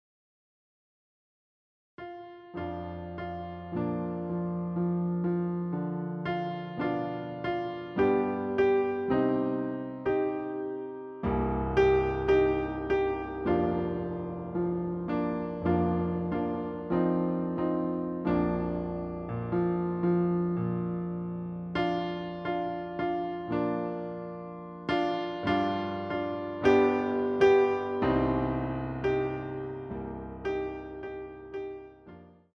CD quality digital audio
using the stereo sampled sound of a Yamaha Grand Piano.